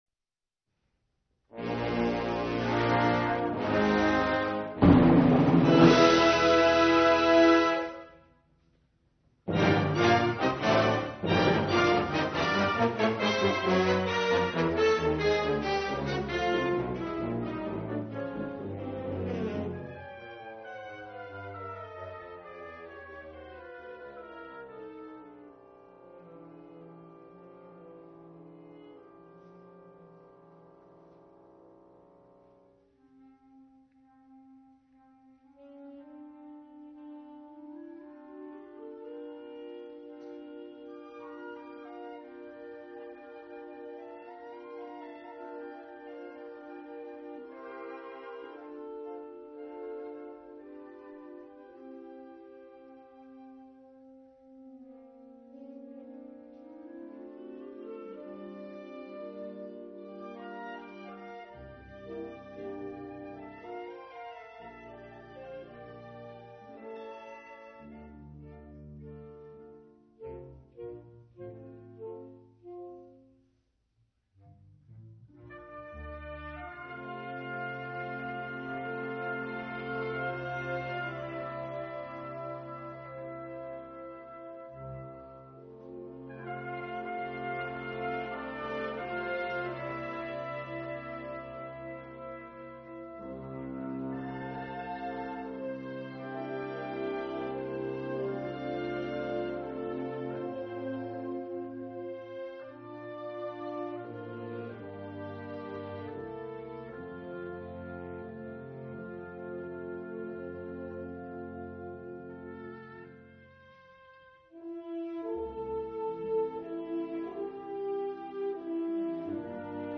Key: Original key